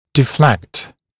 [dɪ’flekt][ди’флэкт]отклонять, отклоняться